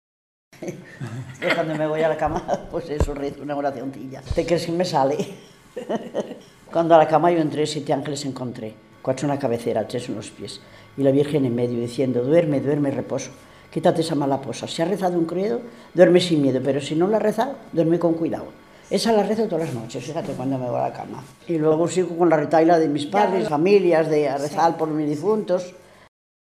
Clasificación: Oraciones
Lugar y fecha de grabación: Cabretón, 18 de julio de 2000.